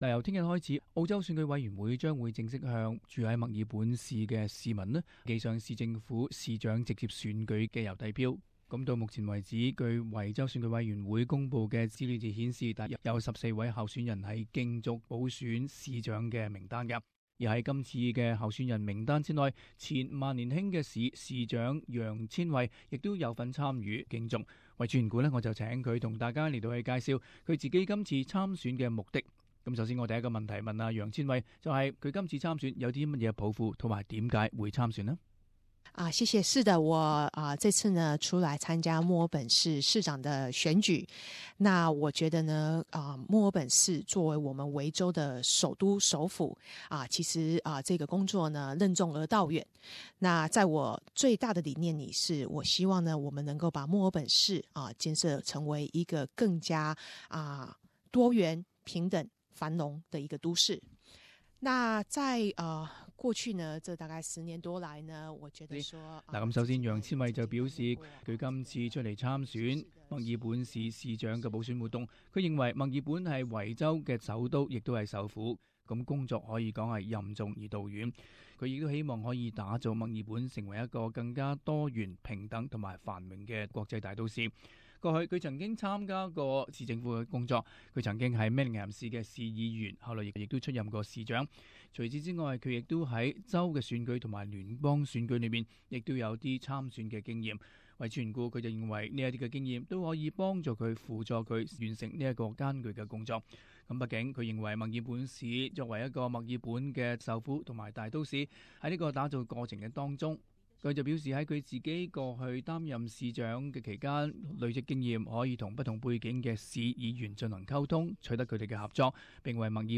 【社團專訪】前萬年興市長楊千惠競選墨爾本市長補選